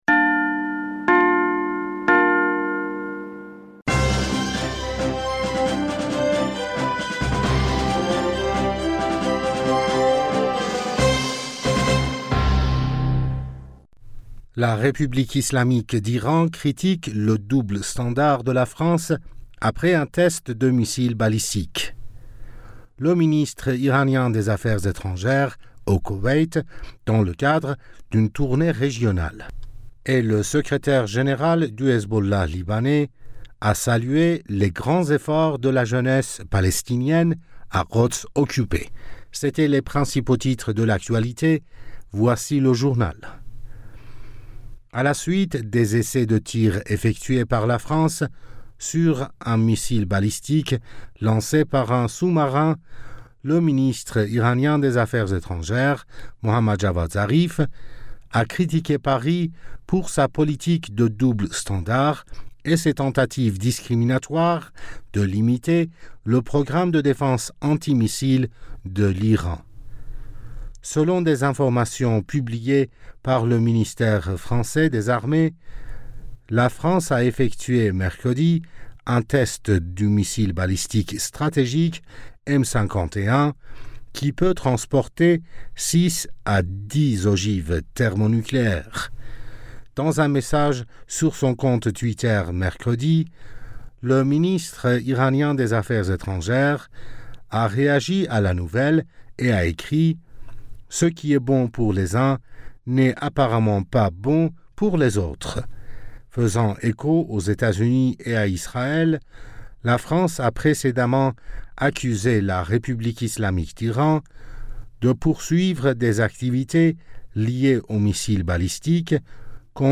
Bulletin d'information du 29 Avril 2021